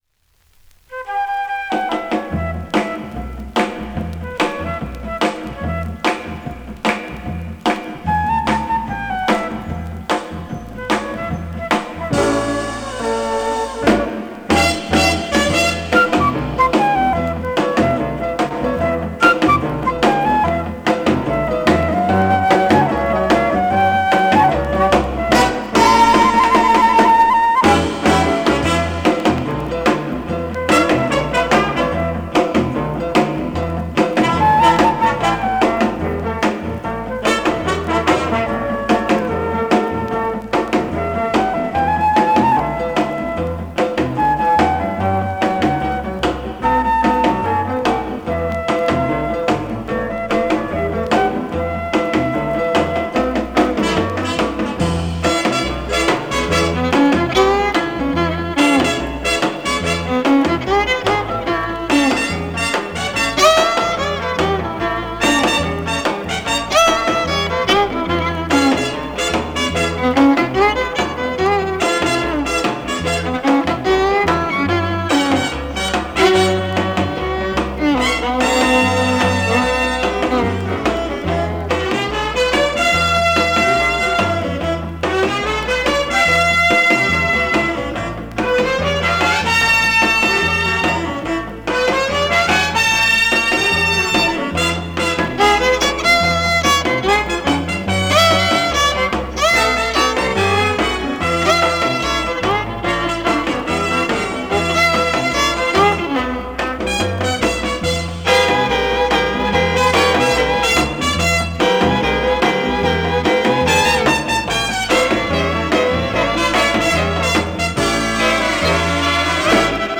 blues single